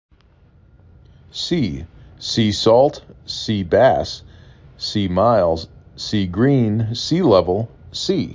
3 Letters, 1 Syllable
2 Phonemes
s E